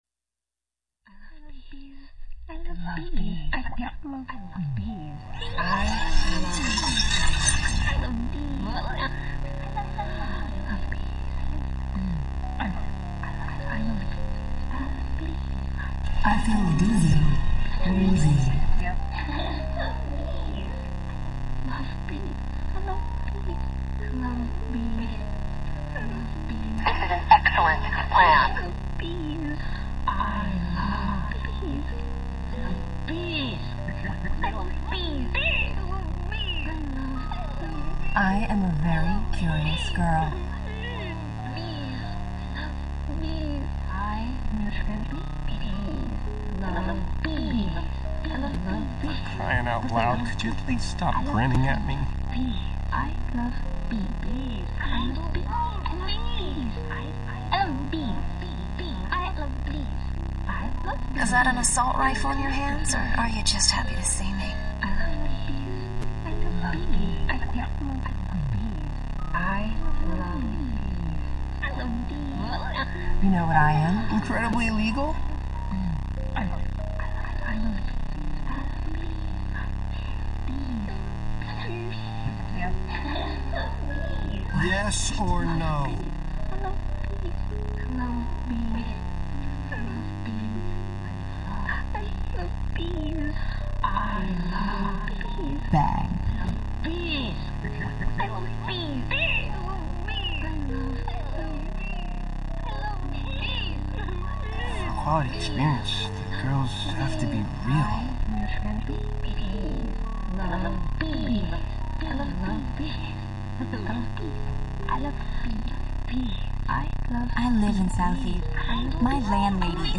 ILB Song ESQ is 2 Tracks (Minimoog and Kalimba), wind comes from a sampler, voice samples are added afterwards from a game